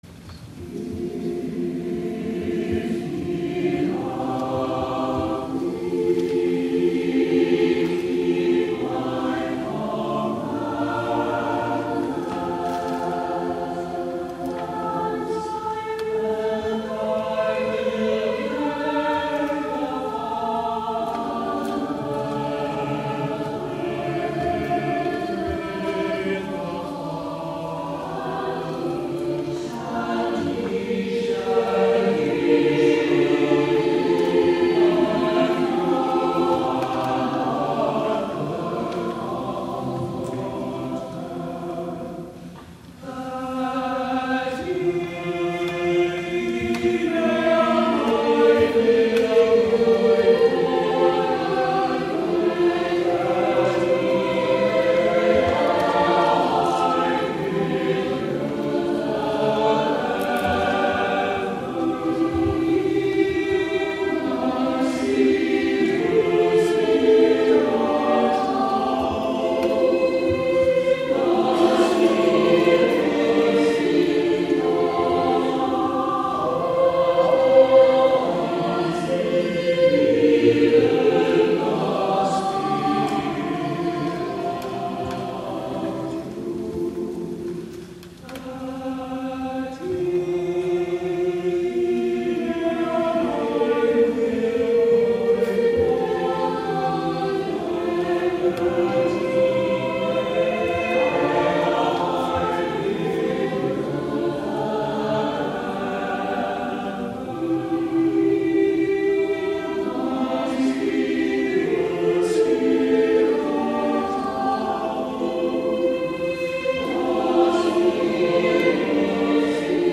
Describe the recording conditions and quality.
11 A.M. WORSHIP